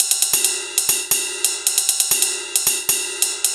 Ride Loop 135bpm.wav